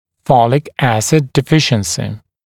[‘fɔlɪk ‘æsɪd dɪ’fɪʃ(ə)nsɪ][‘фолик ‘эсид ди’фиш(э)нси]недостаток фолиевой кислоты